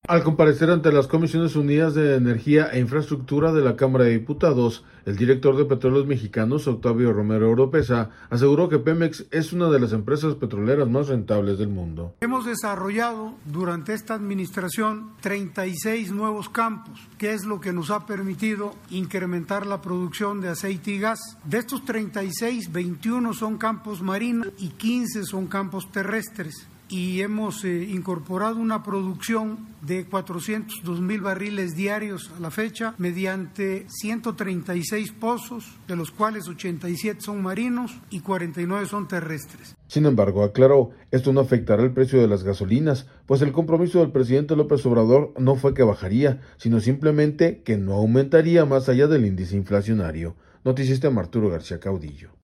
Al comparecer ante las comisiones unidas de Energía e Infraestructura de la Cámara de Diputados, el director de Petróleos Mexicanos, Octavio Romero Oropeza, aseguró que Pemex es una de las empresas petroleras más rentables del mundo.